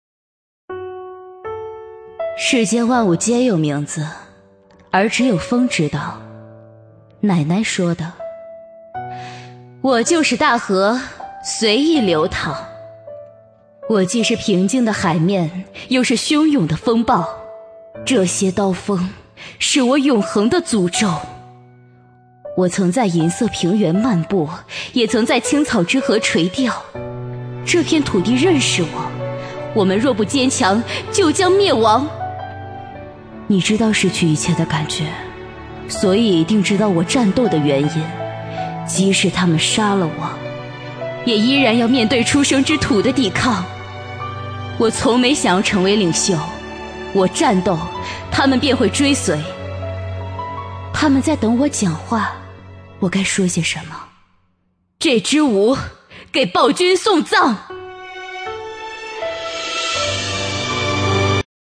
【女13号抖音游戏角色】艾瑞莉娅
【女13号抖音游戏角色】艾瑞莉娅.mp3